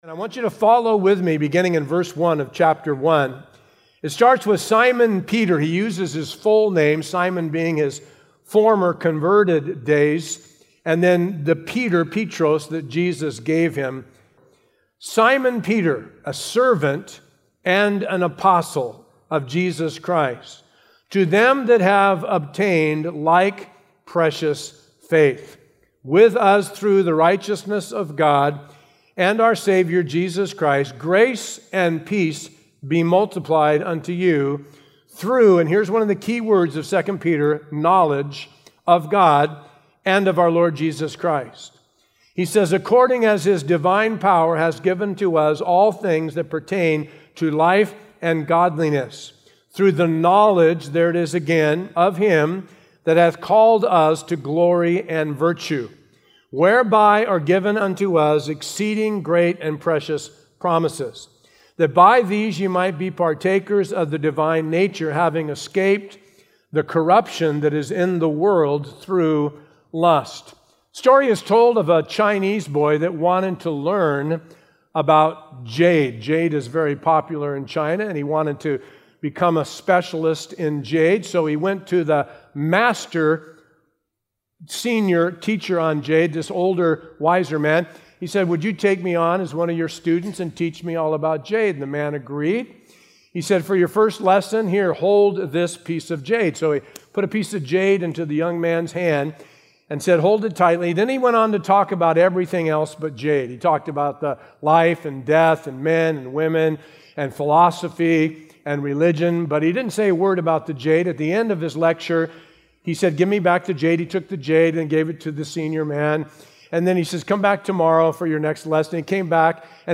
A verse-by-verse expository sermon through 2 Peter 1:1-4